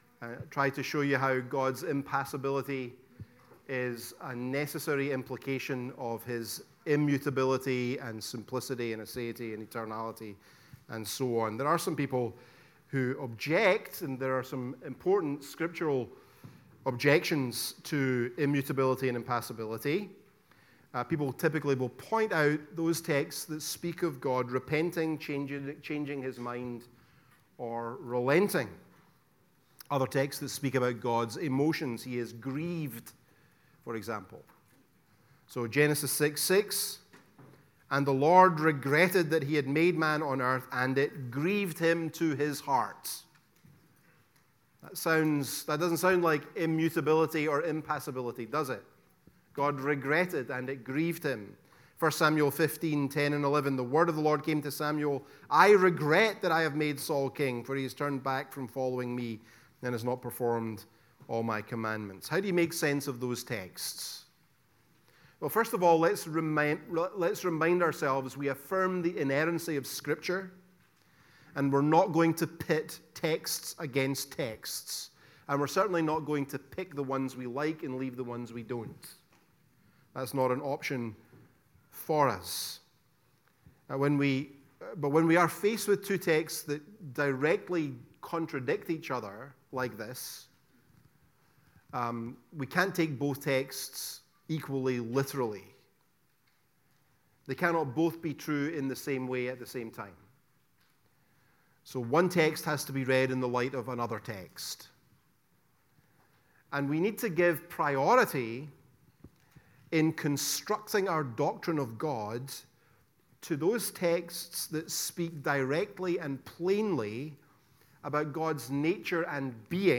The-Doctrine-of-God-Lecture-3-The-Attributes-of-God-Part-2.mp3